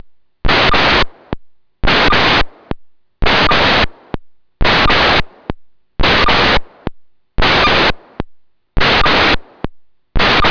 Сигнал на 257,150